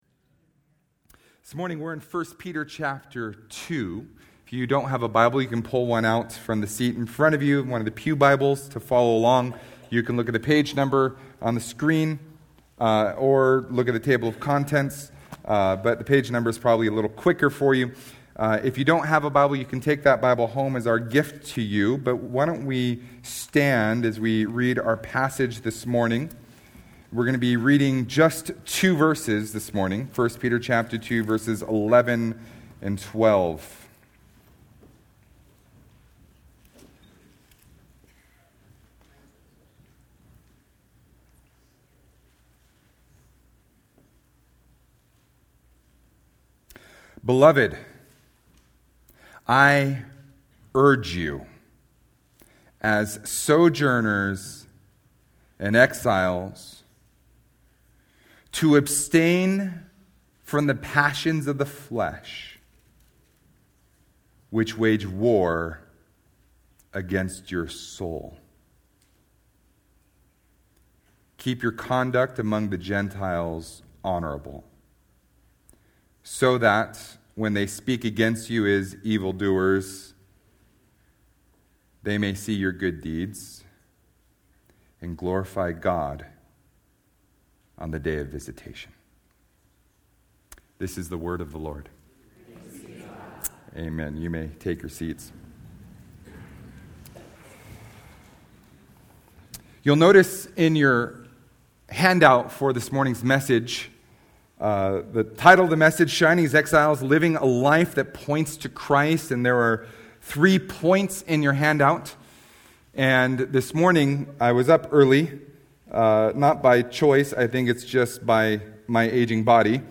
Sermons - Solid Rock Christian Fellowship